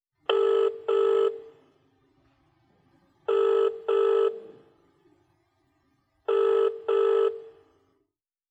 Phonering-otherend.wav